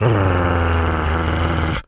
wolf_growl_04.wav